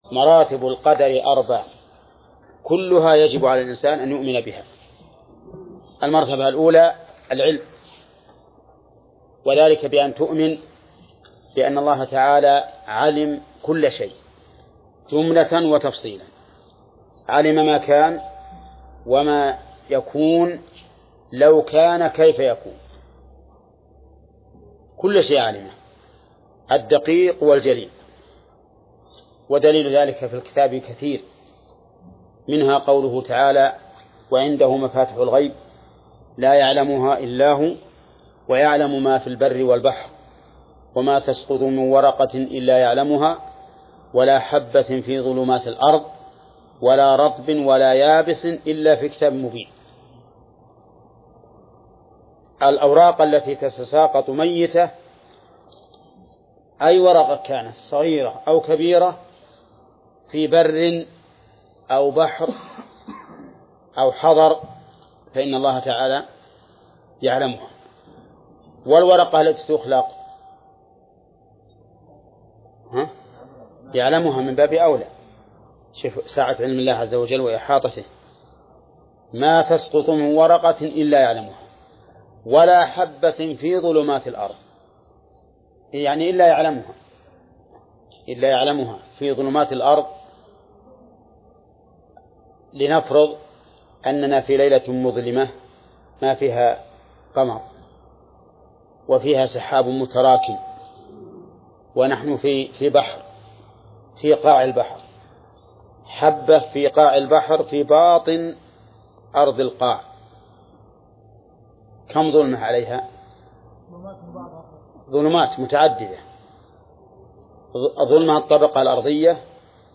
درس 49 / المجلد الثاني : من صفحة: (403)، قوله: (مراتب القدر: ..)..، إلى صفحة: (425)، قوله: (وفي رواية لابن وهب: ..).